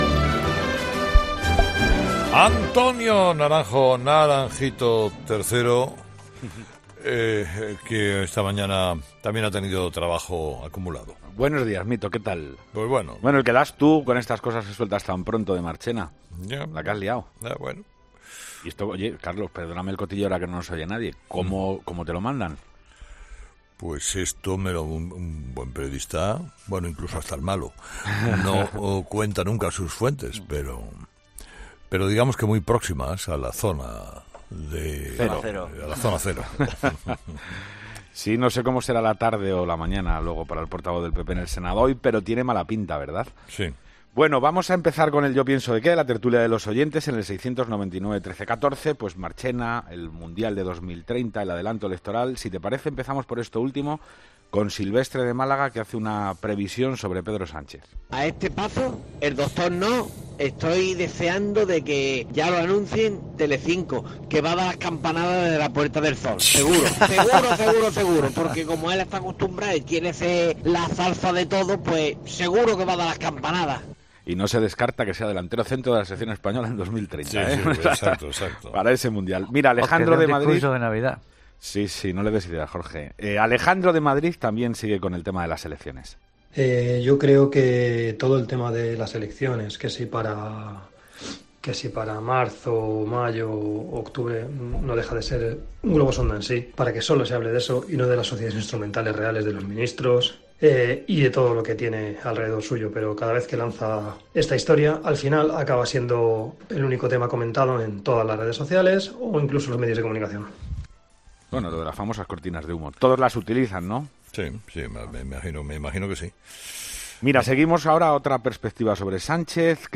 los tertulianos y a la audiencia en un rato de radio y participación